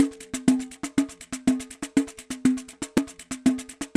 桑巴打击乐 " X Pandeiro 2 Bar C
描述：传统桑巴乐器的循环播放
Tag: 回路 pandeiro